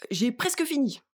VO_ALL_Interjection_07.ogg